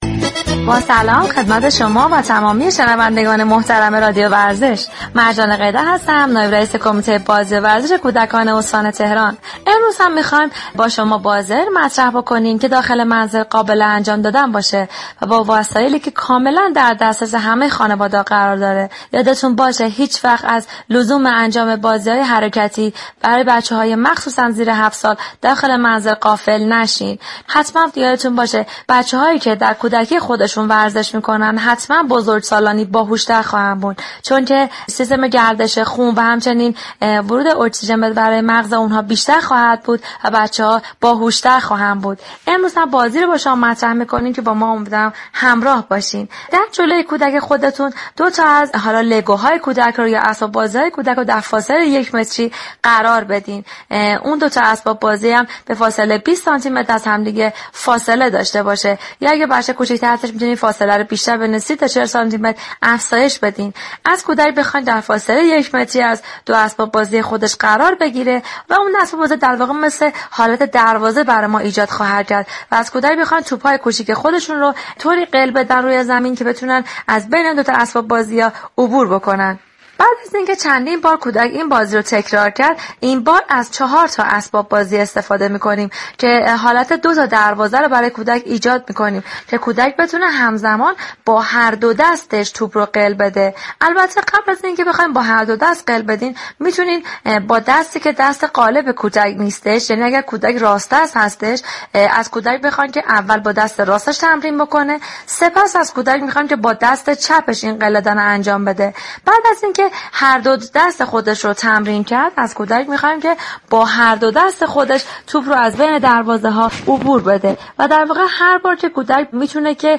در گفتگو با برنامه "گلخونه" رادیو ورزش به تشریح یك نوع توپ بازی ساده و بیخطر برای كودكان با سن كم پرداخت. در این بازی سرگرم كننده كودك شما مهارت غلتاندن توپ را تمرین می كند و با موفقیت در این بازی اعتمادبنفس پیدا كرده و شاداب می شود.